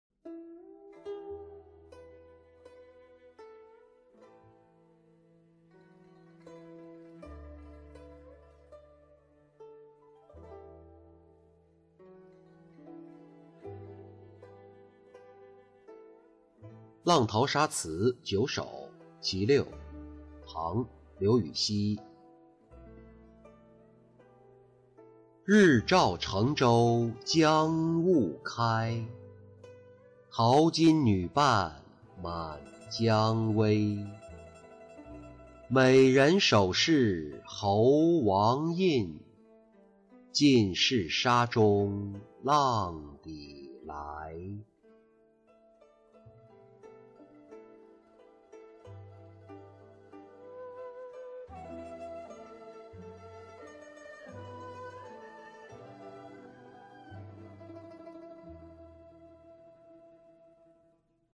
浪淘沙·其六-音频朗读